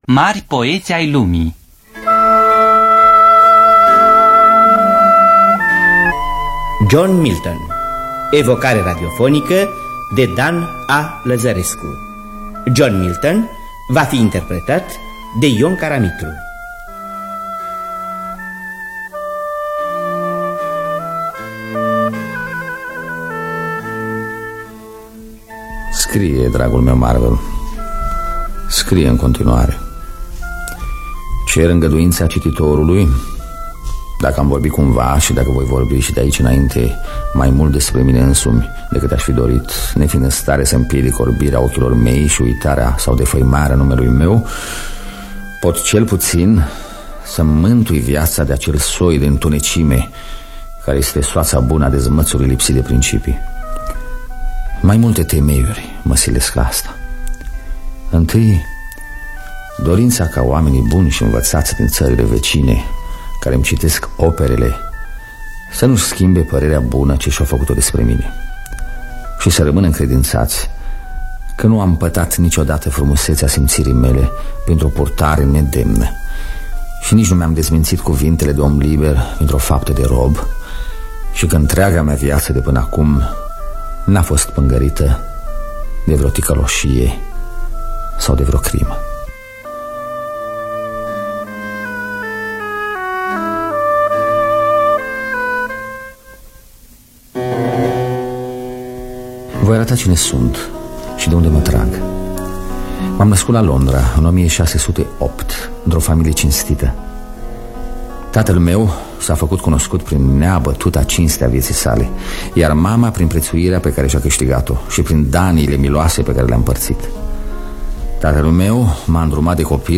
Scenariu radiofonic de Dan Amedeo Lăzărescu.